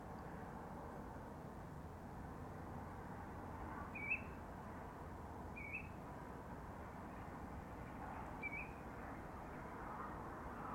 Common Ringed Plover
Charadrius hiaticula